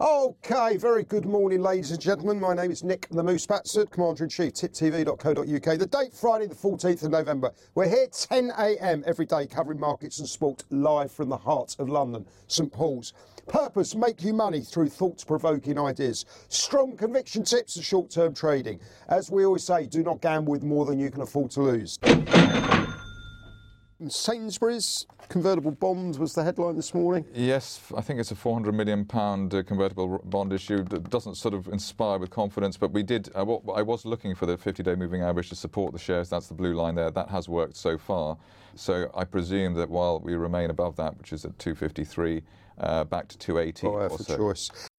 Live market round-up